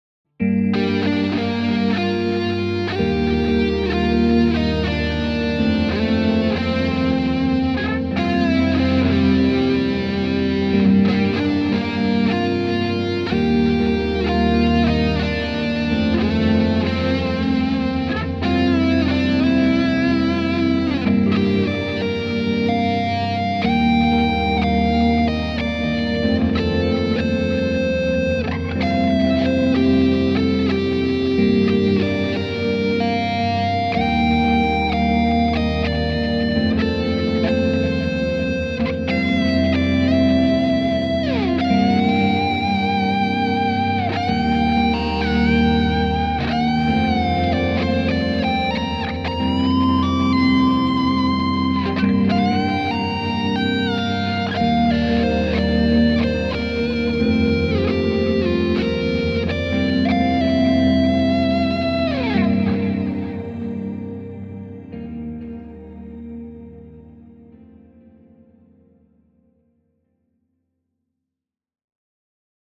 Ambient / Epic Solo